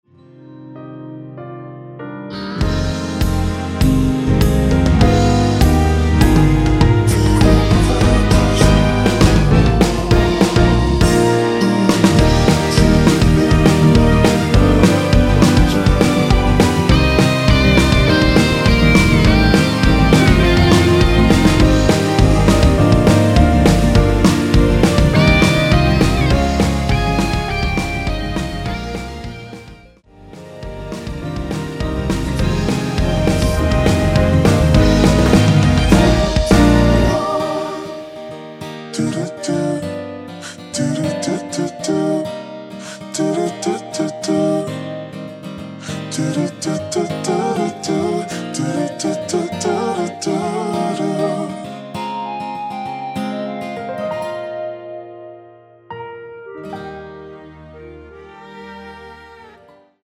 원키에서(-3)내린 멜로디와 코러스 포함된 MR입니다.(미리듣기 확인)
Eb
앞부분30초, 뒷부분30초씩 편집해서 올려 드리고 있습니다.